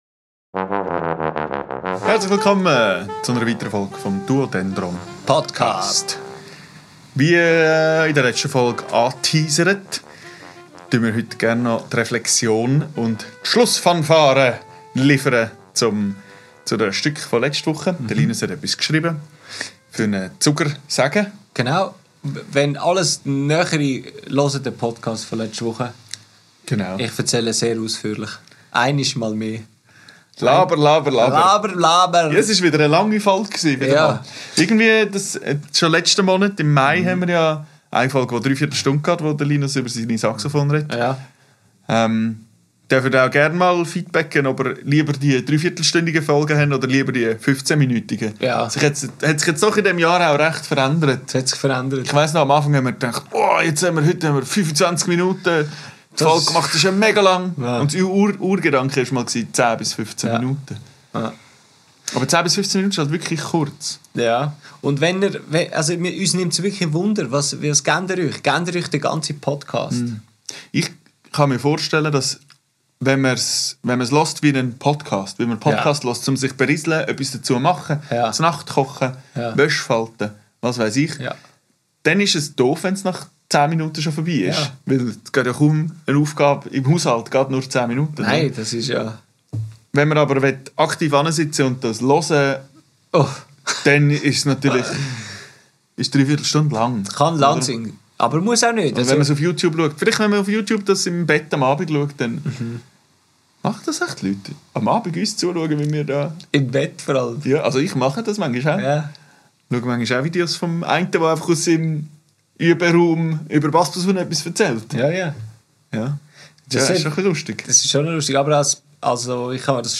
Aufgenommen am 22.05.2025 im Atelier